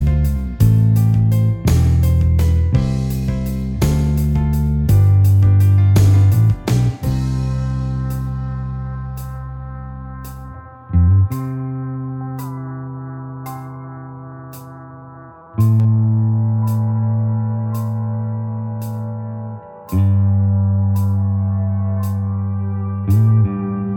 Minus Guitars Indie / Alternative 3:24 Buy £1.50